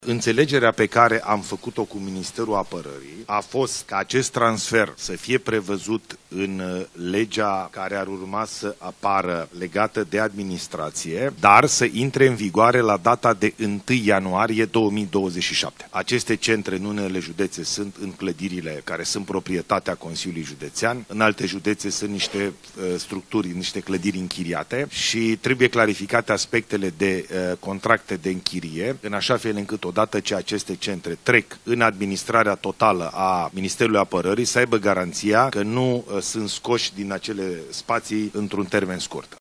Aflat miercuri la întâlnirea anuală cu președinții Consiliilor Județene din toată țara, prim-ministrul a explicat că măsura va fi inclusă în reforma administrației publice, care ar urma să fie adoptată săptămâna viitoare.
11feb-11-Bolojan-transferarea-centrelor-militare.mp3